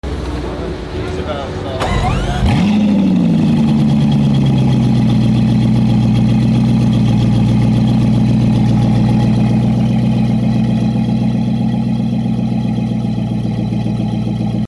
Bugatti Bolide W16 Startup! Sound Effects Free Download